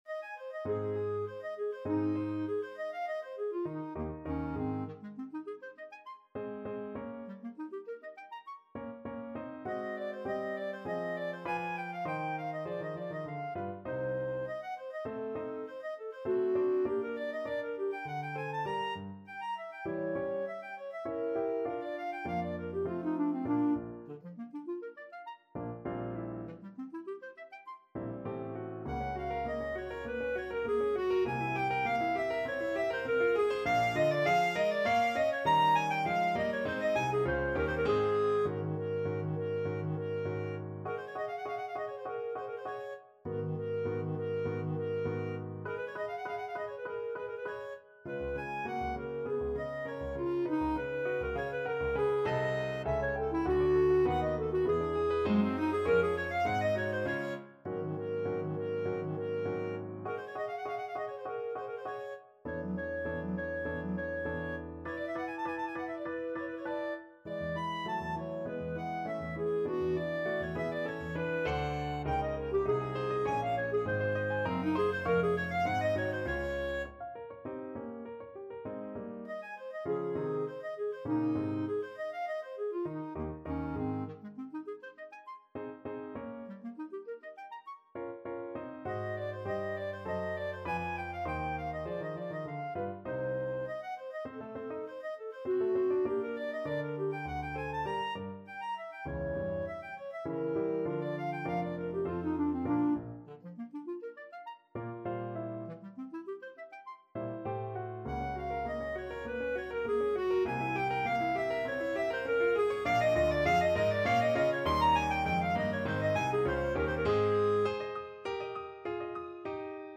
2/2 (View more 2/2 Music)
~ = 200 Allegro Animato (View more music marked Allegro)
Classical (View more Classical Clarinet Music)